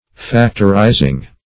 Factorizing - definition of Factorizing - synonyms, pronunciation, spelling from Free Dictionary
(-?zd); p. pr. & vb. n. Factorizing (-?"z?ng).]